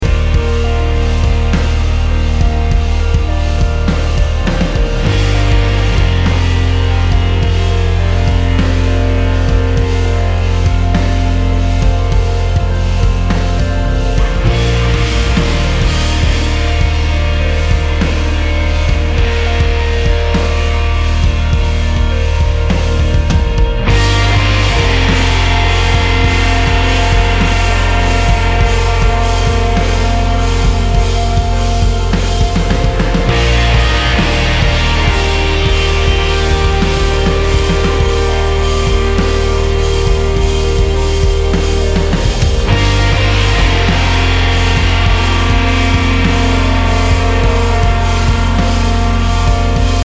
Post Rock, Experimental Rock >